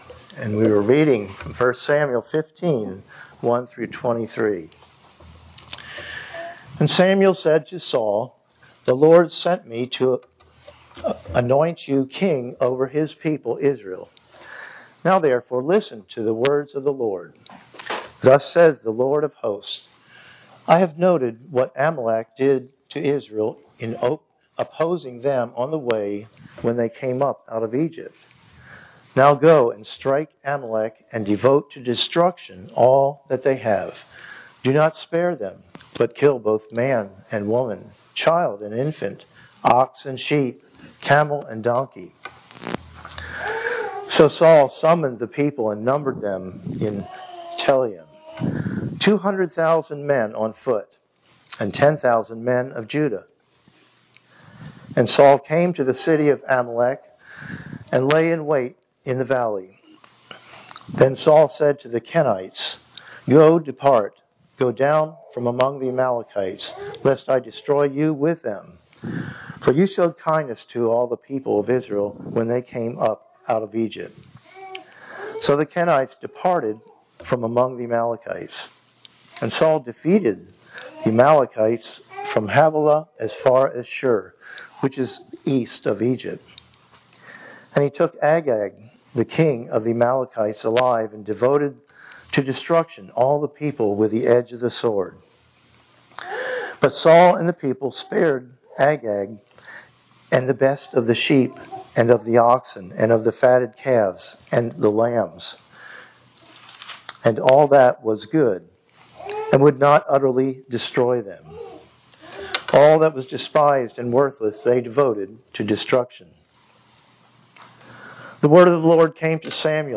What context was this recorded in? Passage: 1 Samuel 15:1-23 Service Type: Sunday Morning Worship